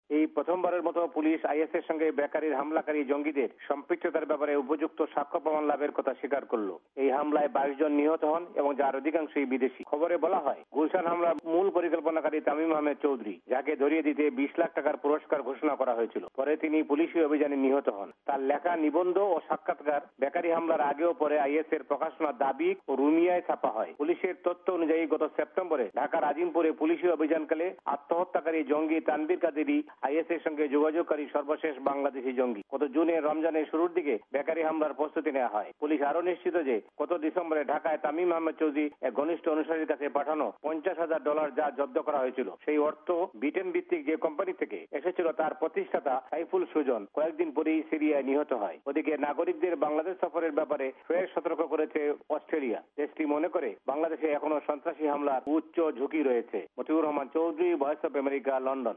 লন্ডন থেকে